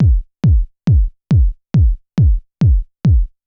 BD        -R.wav